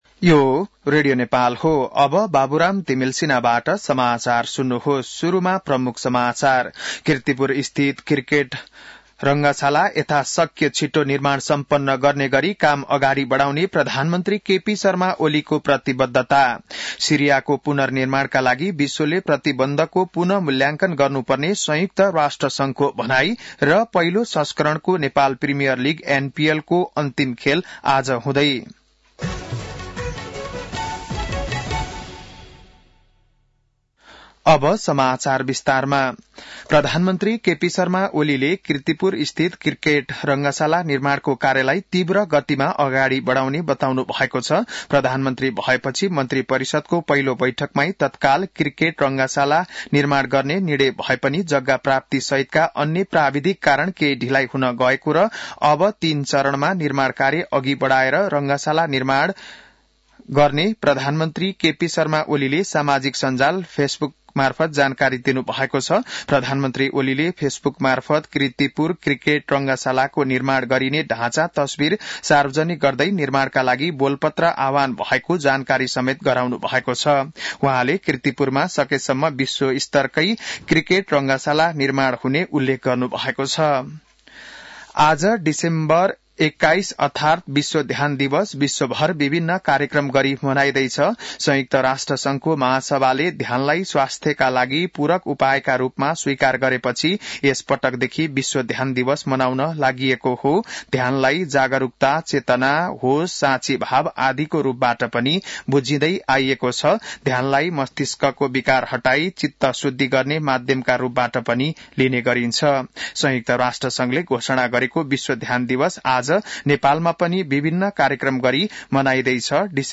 An online outlet of Nepal's national radio broadcaster
बिहान ९ बजेको नेपाली समाचार : ७ पुष , २०८१